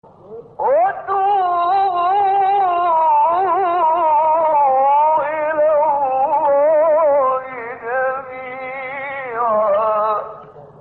هشت فراز در مقام «سه‌گاه» با صوت محمد عمران
گروه فعالیت‌های قرآنی: فرازهایی در مقام سه‌گاه با صوت شیخ محمد عمران را می‌شنوید.